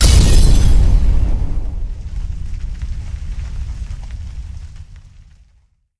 描述：这是一个来自个人文件的声音汇编，我使用ZOOM R16与板载冷凝器和多个麦克风录制：257's,58,AT2020 ...每个通道被各种eqing技术塑造，立体声频谱扩展器，音高转移，最后压缩，加上...其他声音被纳入以加强某些恶化的频率从freesound用户文件.
标签： 碎片 爆炸 爆炸
声道立体声